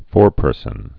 (fôrpûrsən)